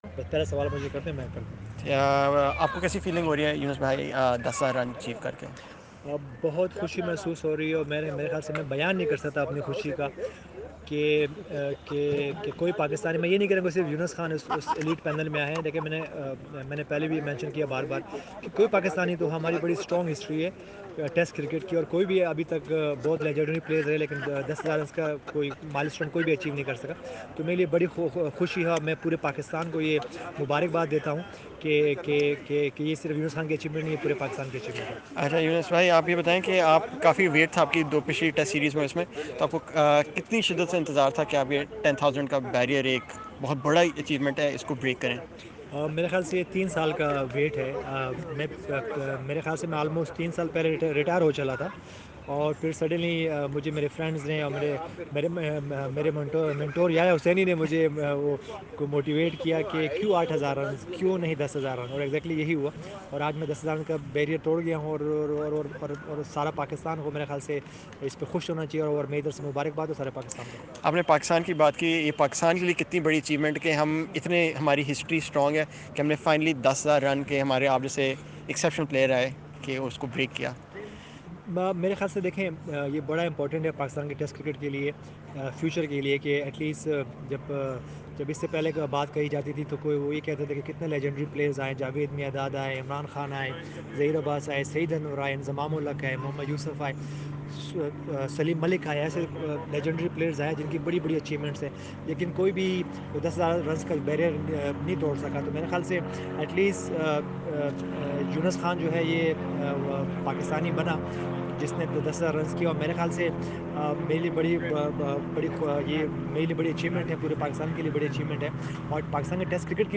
Younis Khan interview on becoming first player to score 10,000 Test runs for Pakistan